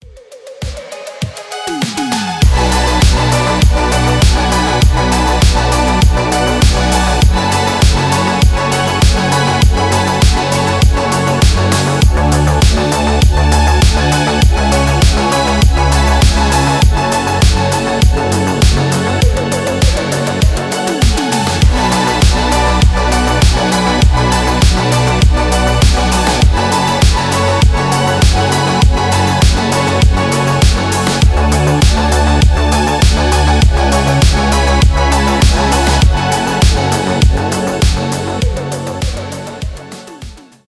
electronic
synthwave